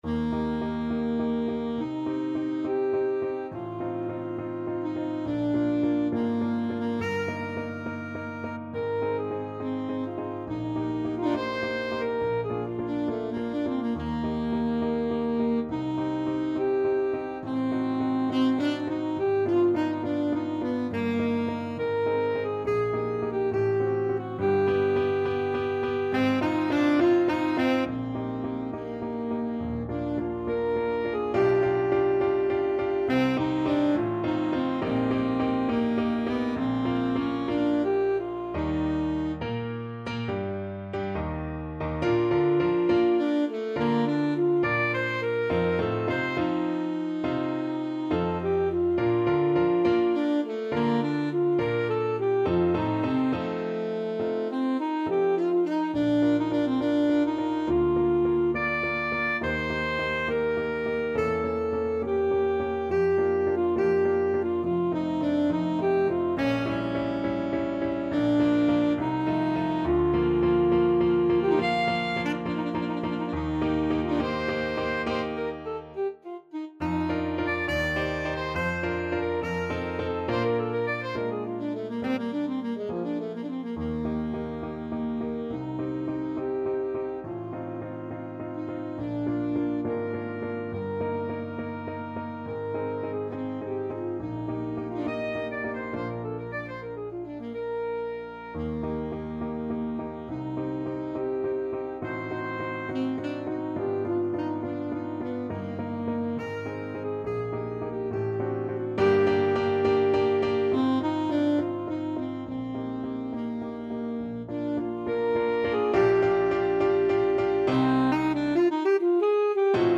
Alto Saxophone version
Classical (View more Classical Saxophone Music)